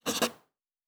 pgs/Assets/Audio/Fantasy Interface Sounds/Writing 10.wav at master
Writing 10.wav